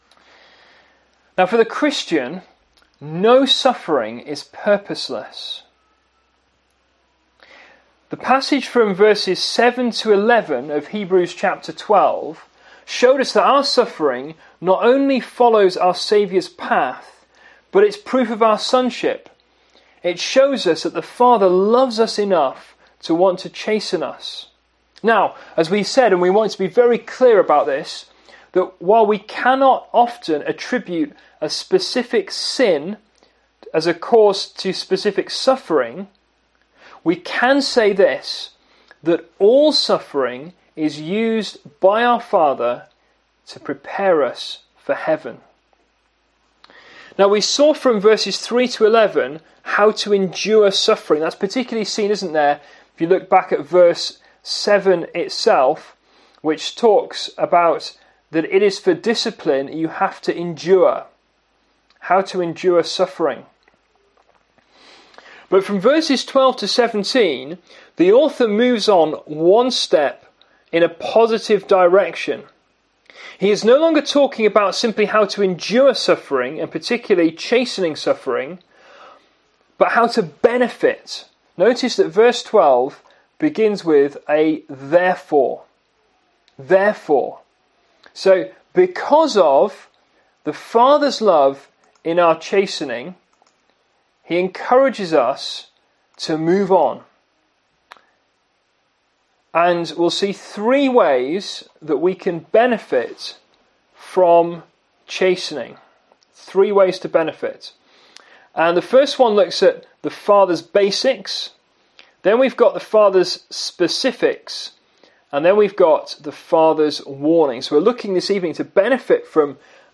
Bible Text: Hebrews 12:12-17 | Preacher
Service Type: PM